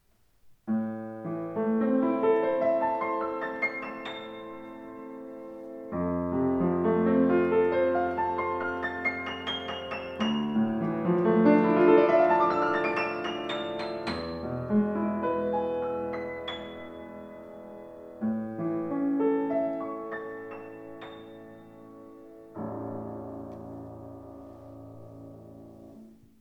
Dämpfungspedal (Moderator), großes Tonvolumen dank neuer Konstruktion mit großzügig ausgelegtem Resonanzboden aus ausgesuchter Bergfichte.
Klaviere